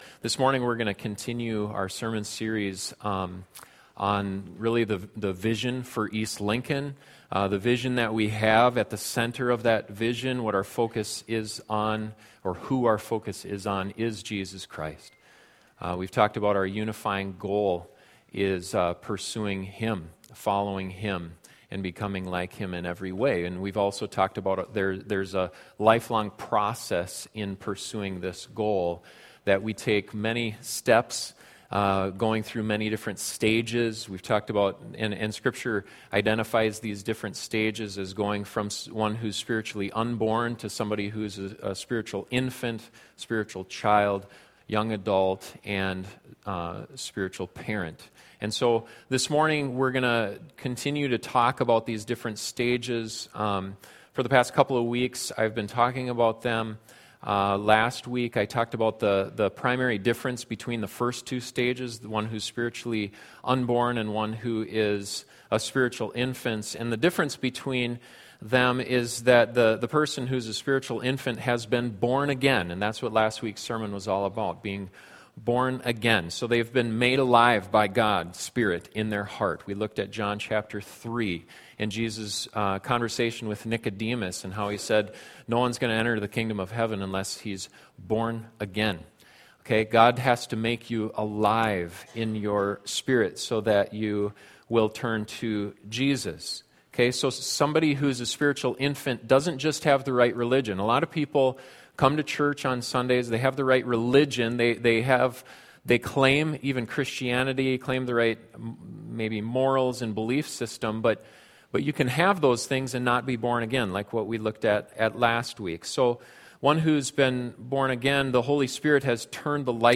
This is the fourth sermon in the Vision for East Lincoln series.